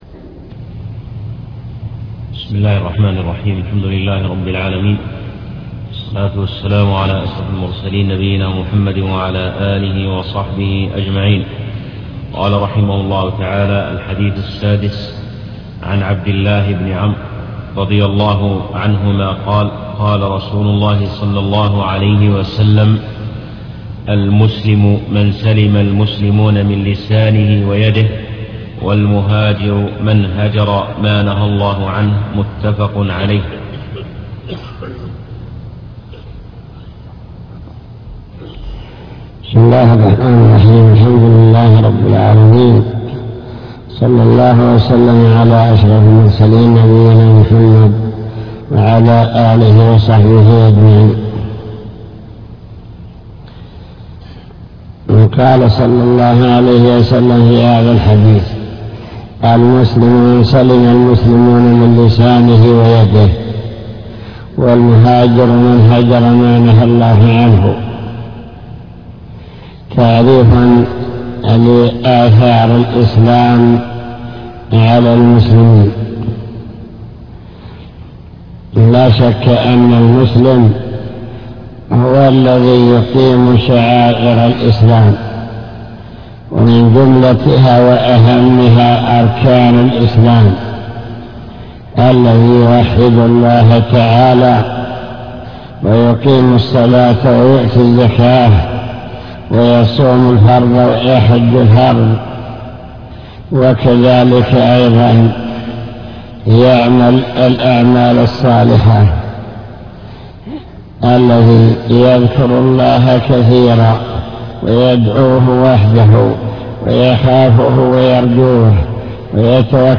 المكتبة الصوتية  تسجيلات - كتب  شرح كتاب بهجة قلوب الأبرار لابن السعدي شرح حديث المسلم من سلم المسلمون